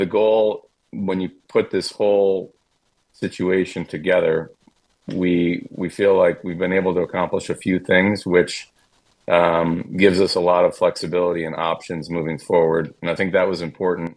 Bowman continued by saying their offseason isn’t over but this gives them extra assets in building a champion caliber team.